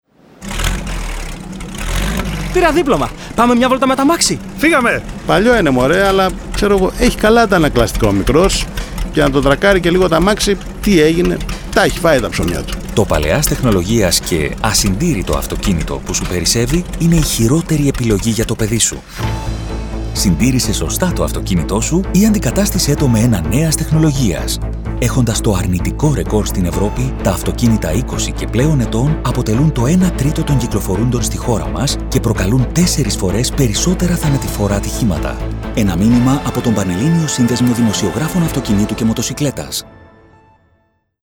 Ραδιοφωνικά μηνύματα σχετικά με τη δημόσια υγεία, την οδική ασφάλεια και την εξυπηρέτηση της κυκλοφορίας στα αστικά δίκτυα, μεταδίδονται από αρχές Ιουλίου (προς το παρόν σε περιορισμένο δίκτυο ραδιοφωνικών σταθμών του Λεκανοπεδίου).